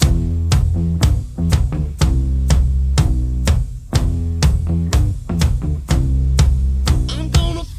Here’s a quick thing i sampled from YouTube inside of LoopyPro using an auv3 app which lets you host YouTube inside of an auv3 instrument wrapper: